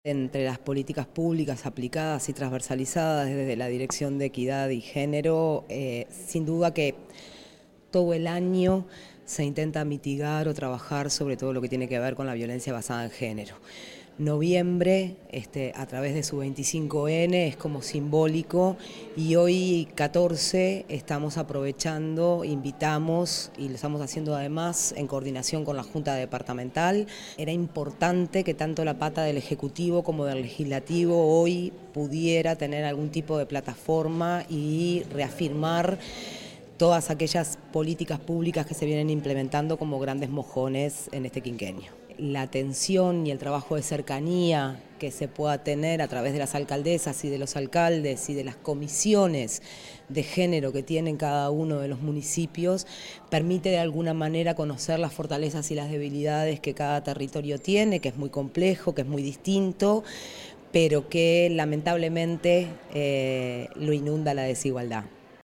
La actividad se desarrolló en la Sala Beto Satragni del Complejo Cultural Politeama – Teatro Atahualpa del Cioppo, en presencia del Secretario General de la Intendencia de Canelones, Dr. Esc. Francisco Legnani, la Pro Secretaria General, As. Soc. Silvana Nieves, el Pro Secretario General, Marcelo Metediera, la Directora de Género y Equidad, Nohelia Millán, demás directoras y directores de la Intendencia, alcaldesas y alcaldes y otras autoridades departamentales y locales.